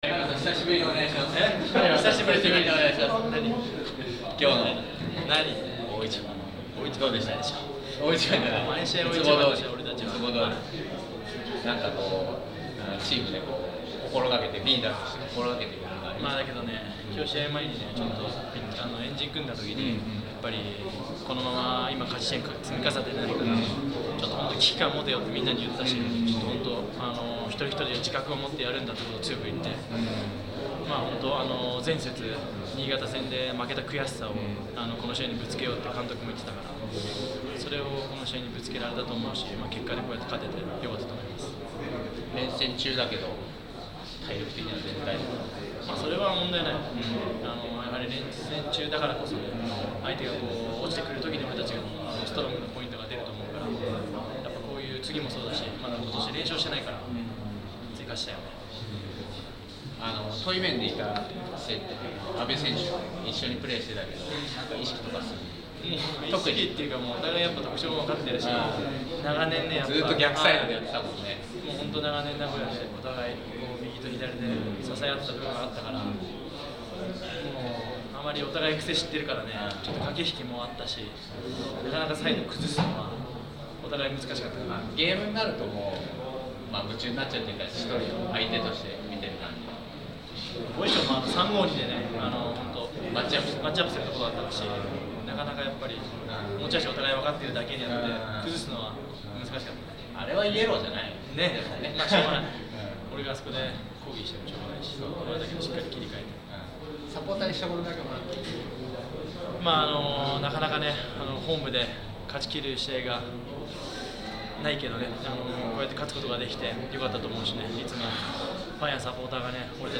2015 J1 1st 10節 ヴァンフォーレ甲府戦 田中 隼磨インタビュー